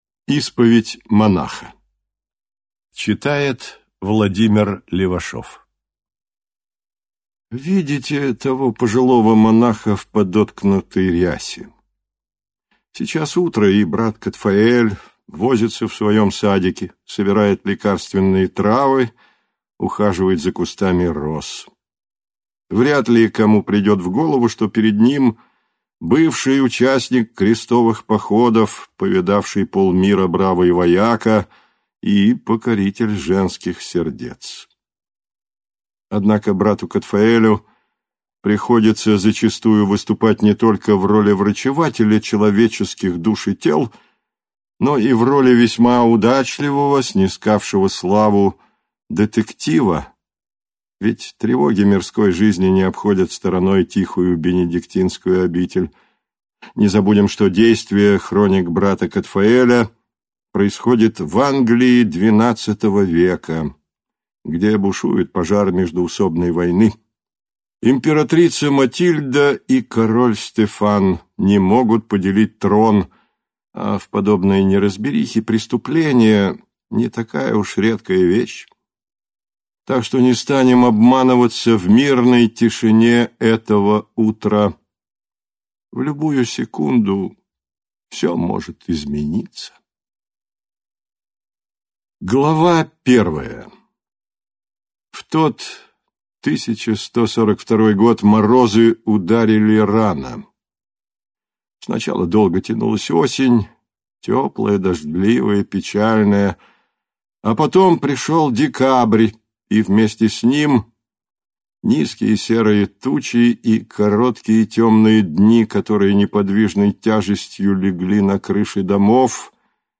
Аудиокнига Исповедь монаха | Библиотека аудиокниг